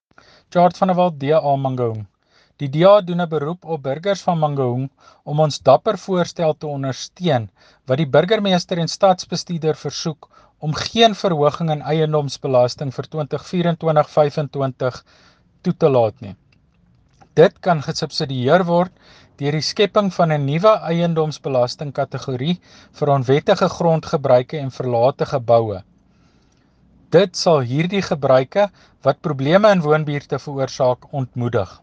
Afrikaans soundbites by Cllr Tjaart van der Walt and